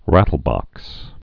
(rătl-bŏks)